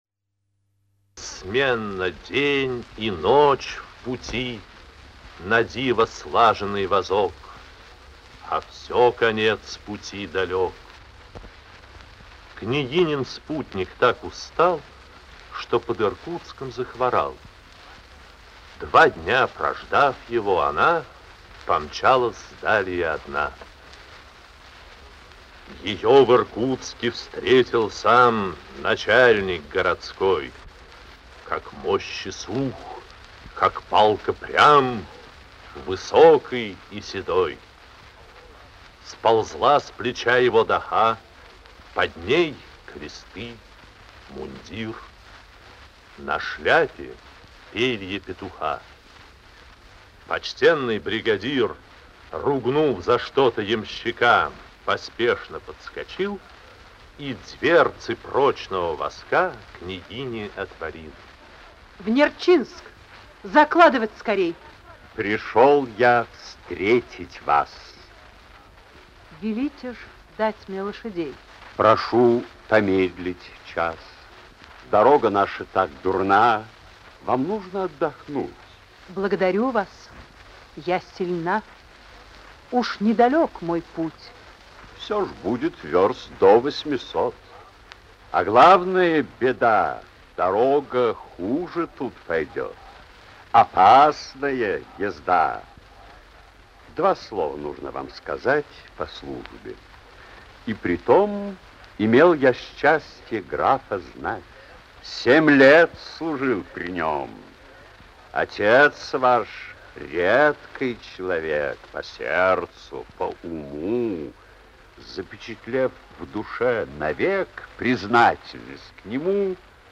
Аудиокнига Русские женщины
Качество озвучивания весьма высокое.